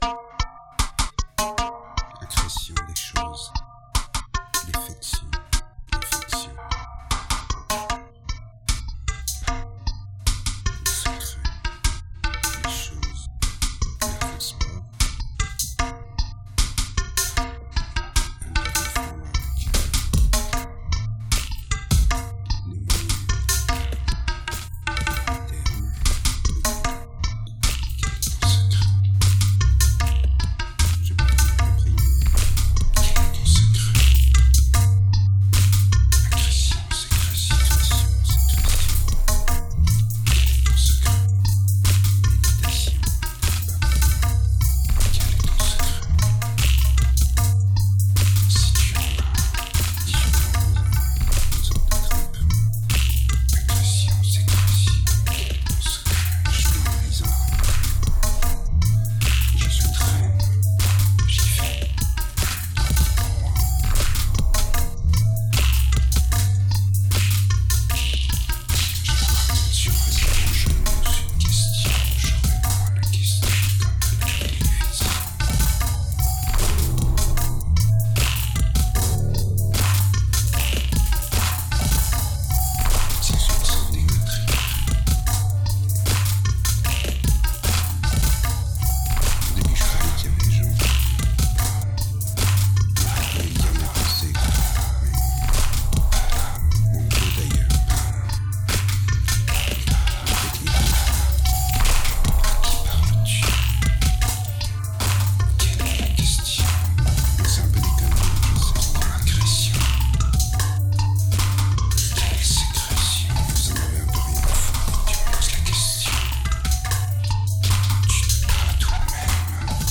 a dark album, fast and poorly recorded during late 2007